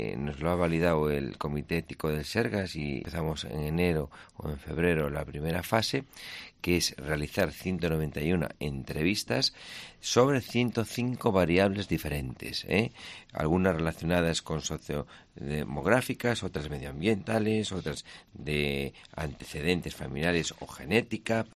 habla del proyecto de centenarios en los estudios de Cope Ourense